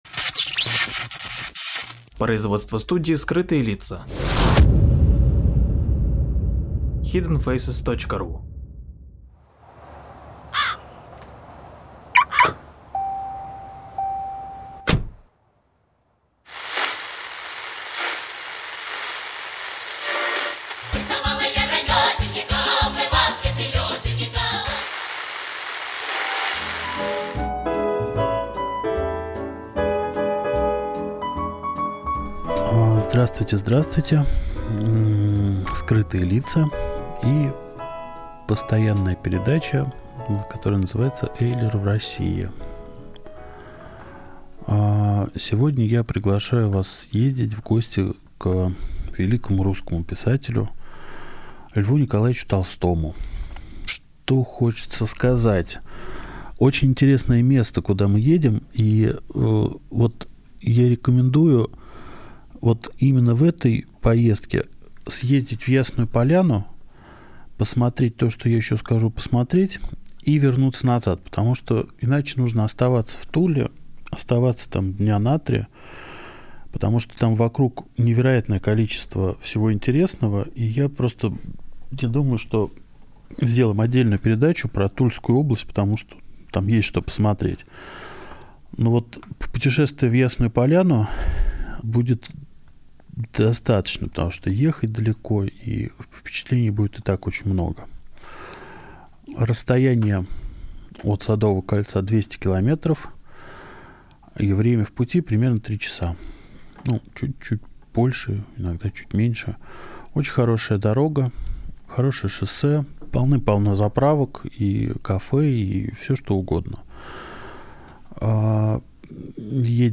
Художественно-литературная, музыкальная, авторская прогулка, по самому прекрасному городу на земле! Санкт-Петербург пешком.